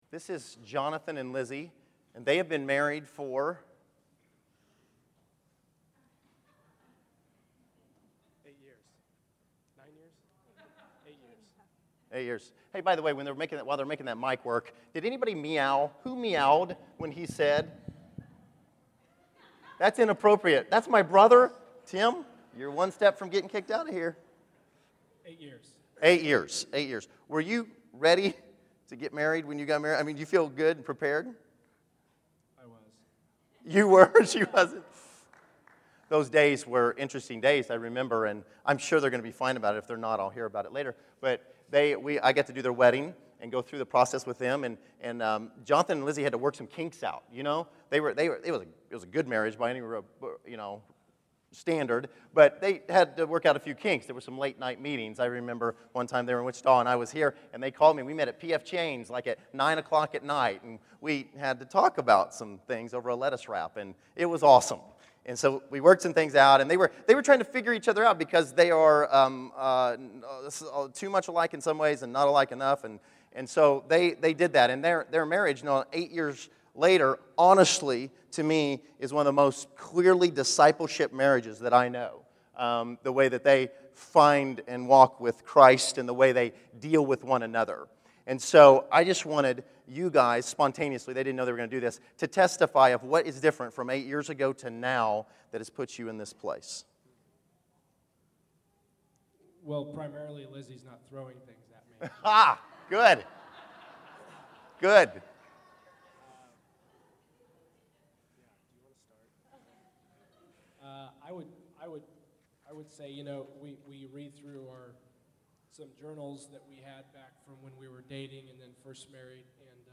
May 05, 2013      Category: Testimonies      |